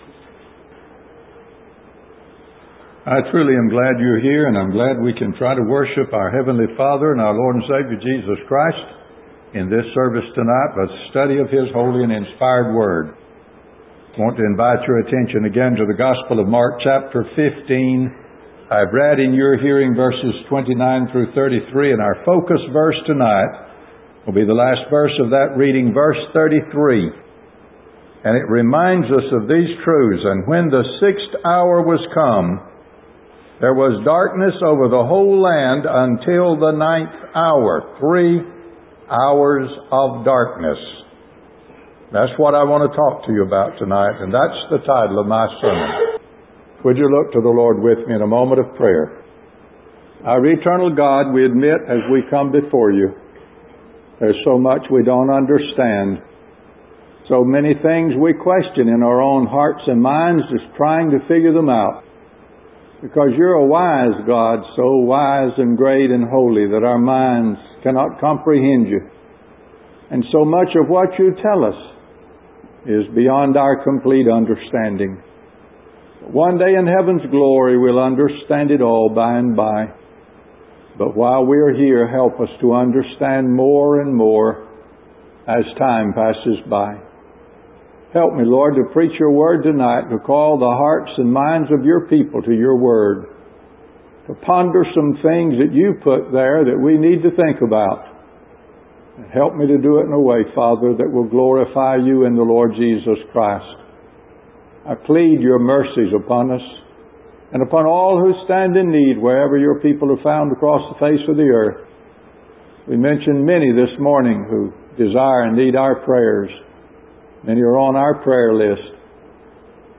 Sermons Previously Used